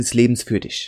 I couldnt fix it with De-clicker and noise reduction. I cant cut the clap either, because it is as long as the word I speak.